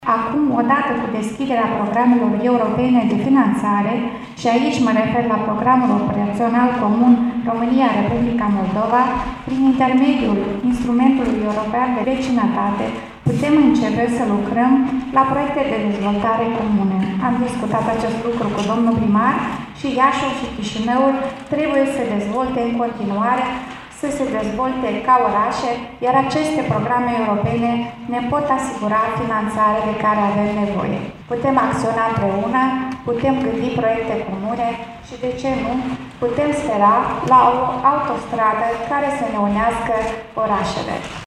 Astăzi, cu ocazia manifestărilor din Piaţa Unirii de la Iaşi, un grup de aproximativ 500 de tineri basarabeni au desfăşurat un drapel tricolor pe o lungime de o sută de metri.
În cadrul alocuţiunilor rostite, atât primarul Iaşului, Mihai Chirica, cât şi cel din Chişinău, Silvia Radu, şi-au exprimat speranţa că autostrada Târgu Mureş – Iaşi – Chişinău va fi contruistă cât mai repede, pentru a scoate din izolare partea de răsărit a ţării.